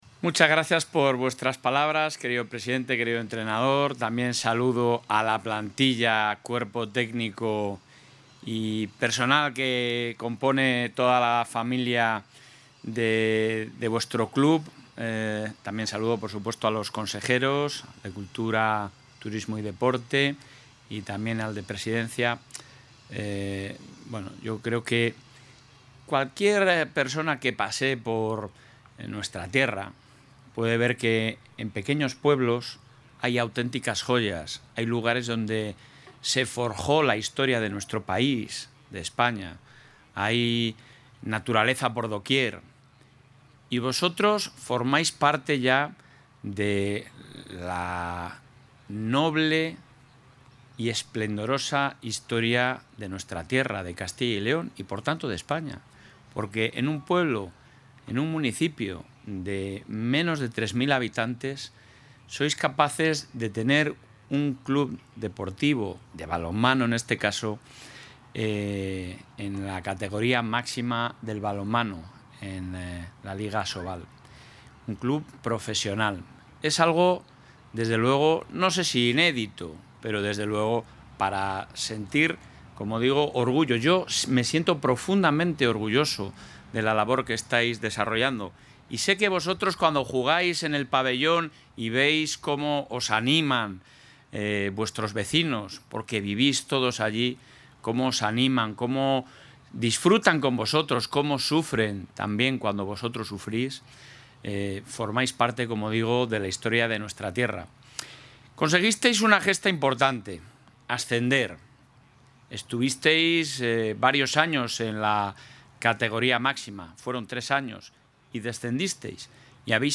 Intervención del presidente de la Junta.
El presidente del Gobierno de Castilla y León ha recibido al equipo segoviano tras su subida a la máxima categoría de balonmano español.